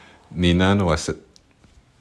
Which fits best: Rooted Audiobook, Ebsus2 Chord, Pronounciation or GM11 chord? Pronounciation